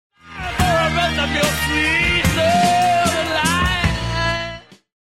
Genere: rock